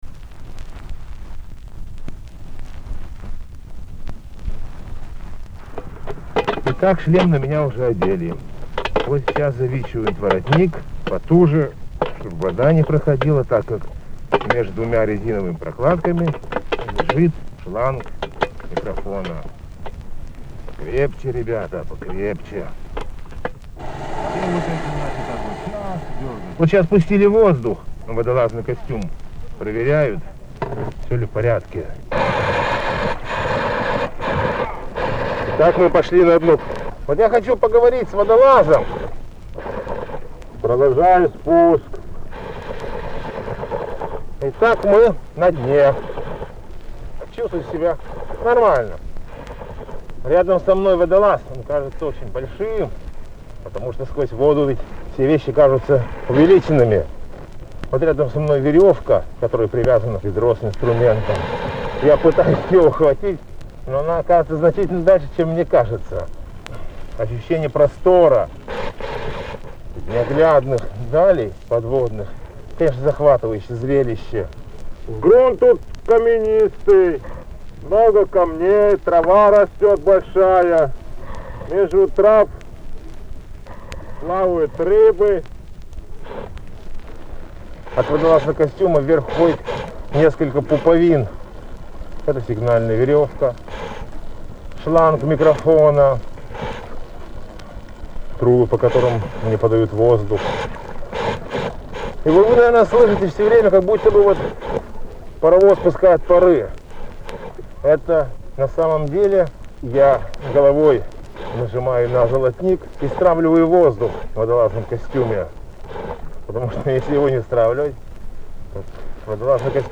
С микрофоном на дне моря
Каспийское море.
Звуковая страница 4 - Фонотека необычного. В подводном цехе Каспия.